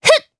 Erze-Vox_Attack2_jp.wav